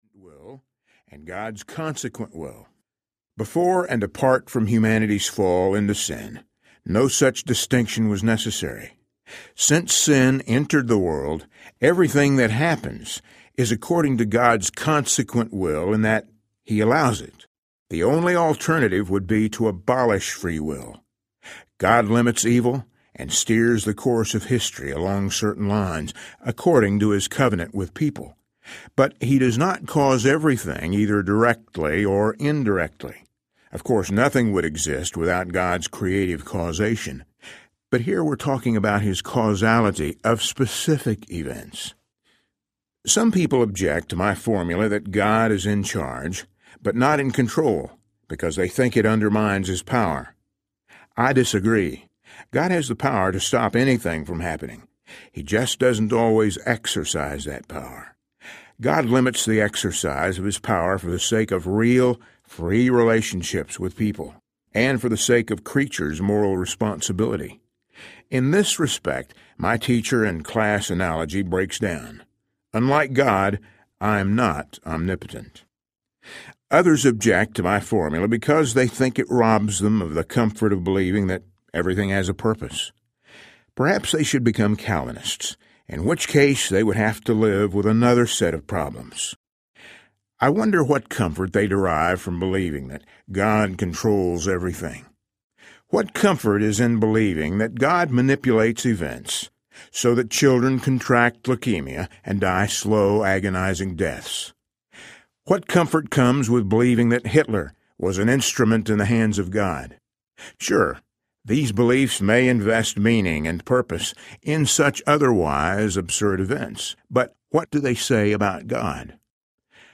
Questions to All Your Answers Audiobook